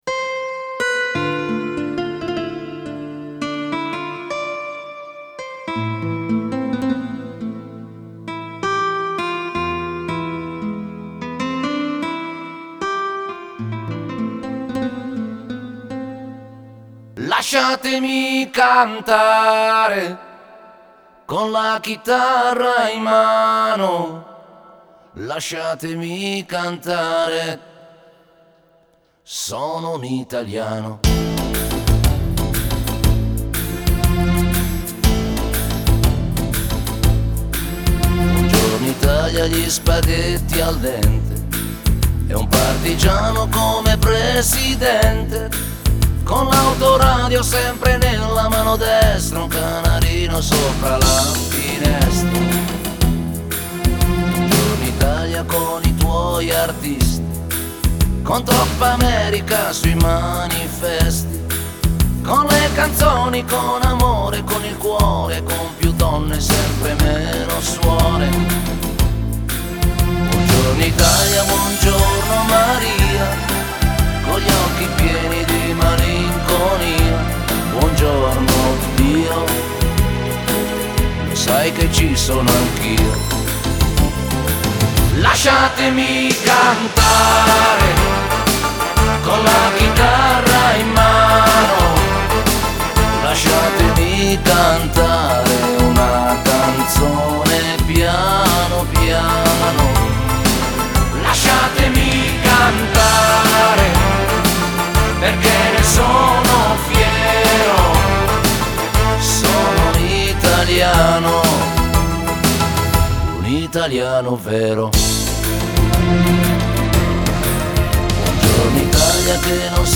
спокойная музыка